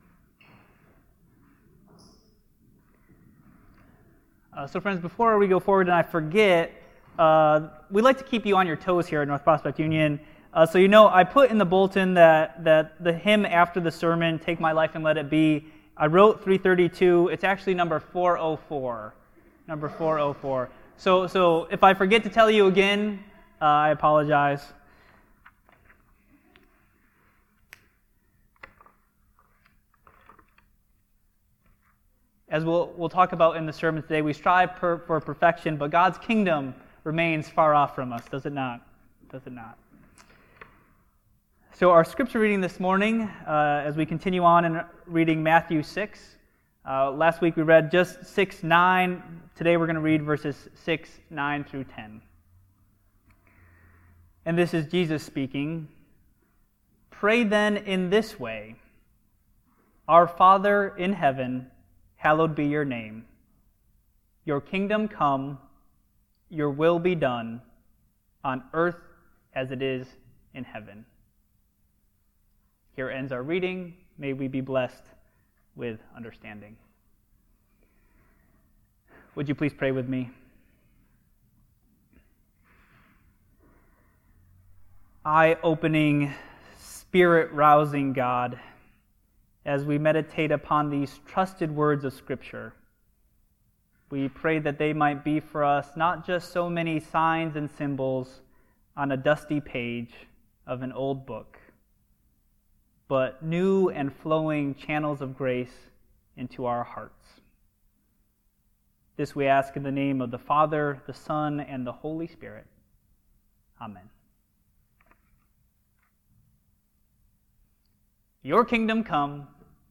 The doxology is a favorite hymn sung by Christians throughout the world.